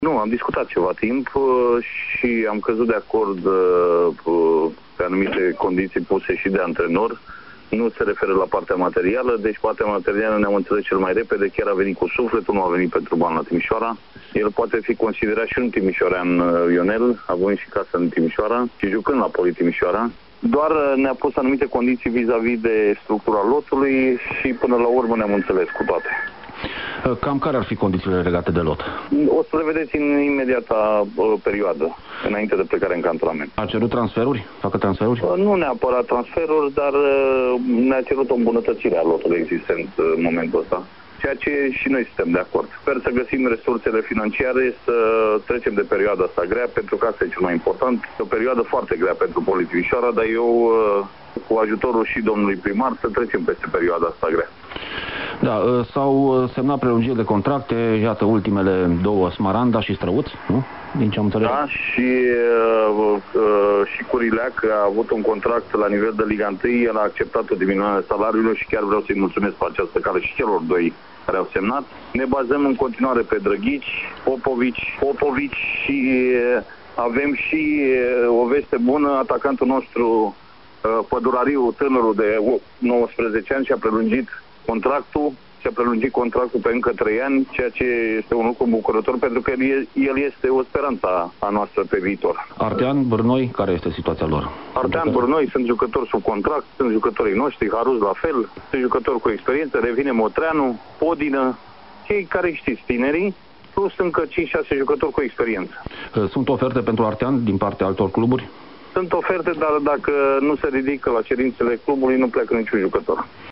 Intervenția telefonică